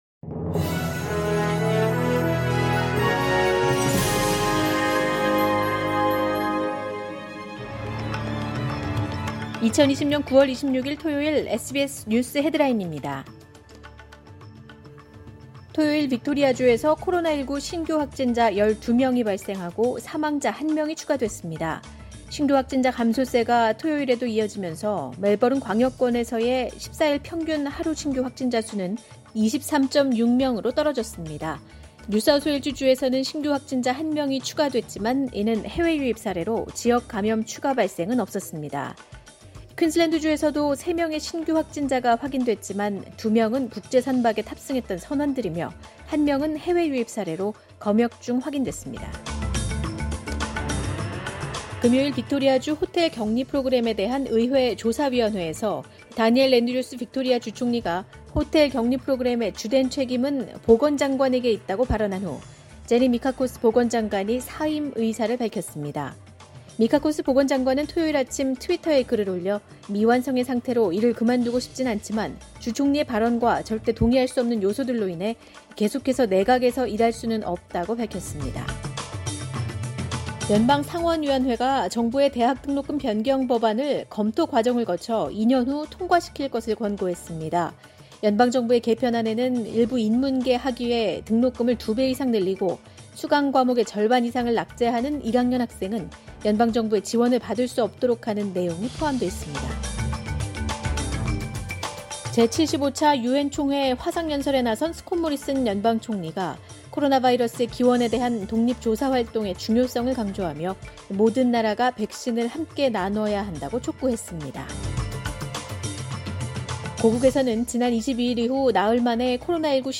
[SBS News Headlines] 2020년 9월 26일 주요 뉴스
2020년 9월 26일 토요일 오전의 SBS 뉴스 헤드라인입니다.